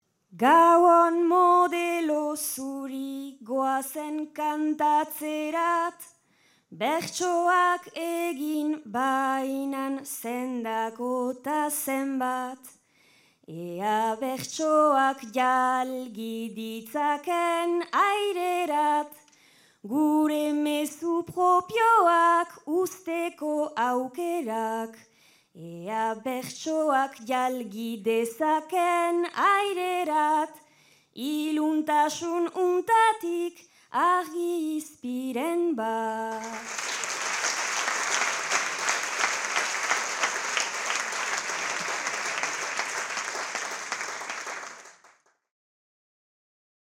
Zarautz (Gipuzkoa)
Agurra.